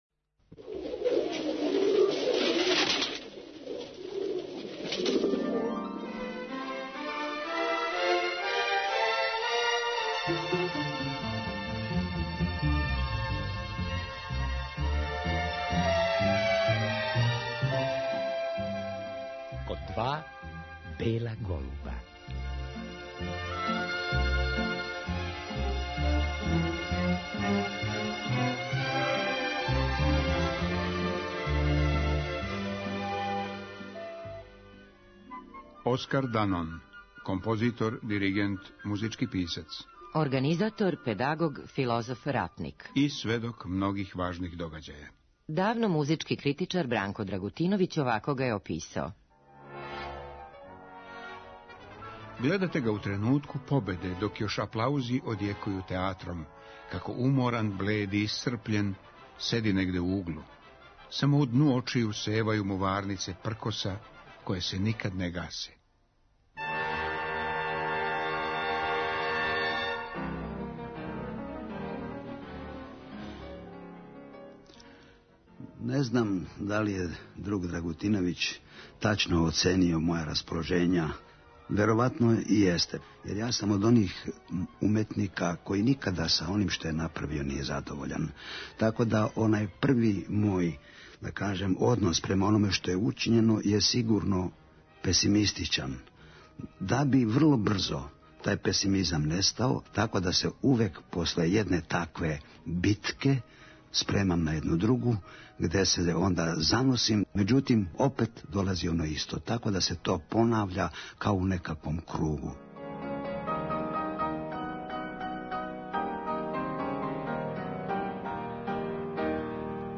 У емисији ћемо чути причу о животу и стваралштву Оскара Данаона. А чућемо и самог Данона како говори своја сећања.